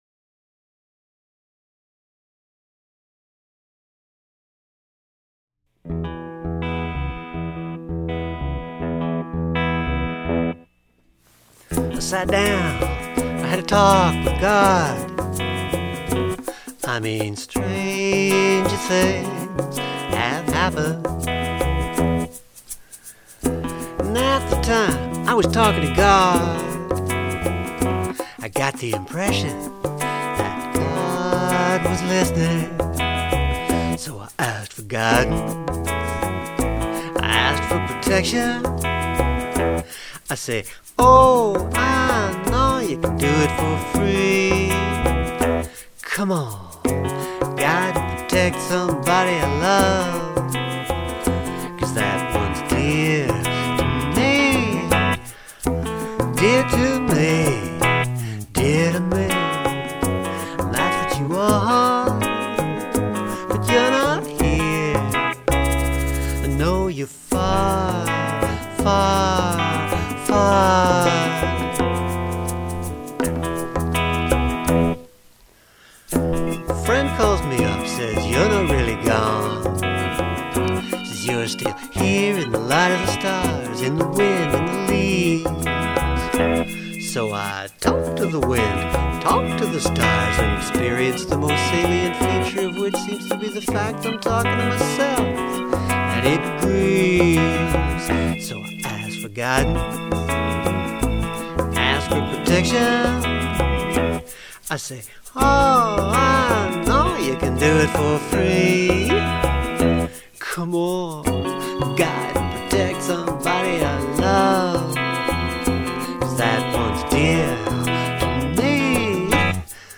I'm still exploring space on vocals in mixes. This one's got two different spaces at least. The bvs are different than the vocal, but the vocal picks up on it after a while and is changed by the encounter with the bvs.
Nothing sounds too dry, right?